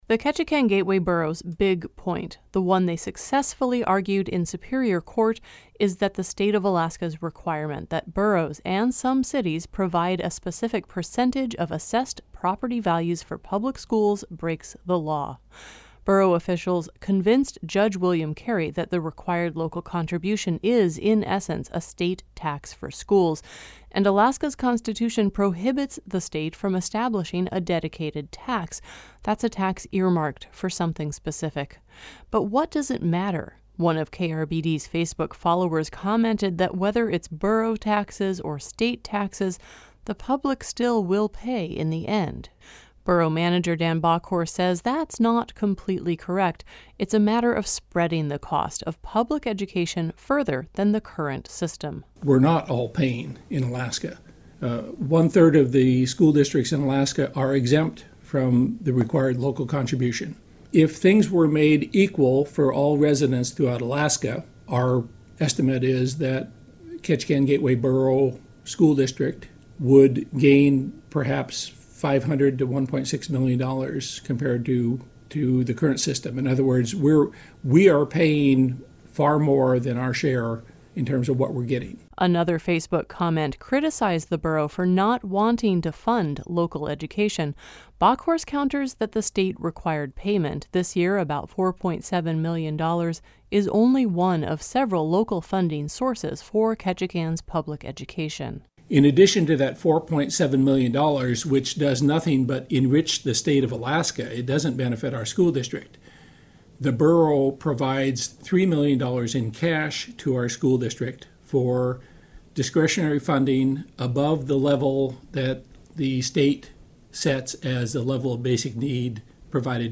I read him a few more comments from social media, to get his responses.